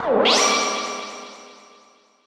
snd_great_shine.ogg